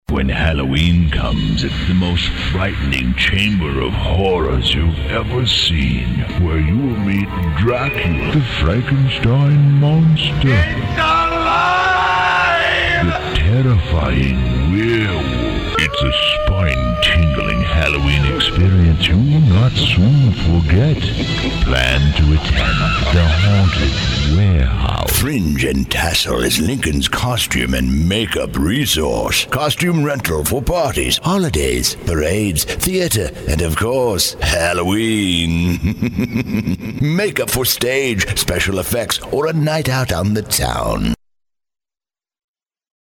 Male
A storyteller with a deep resonant voice.
Halloween Spooky Monsters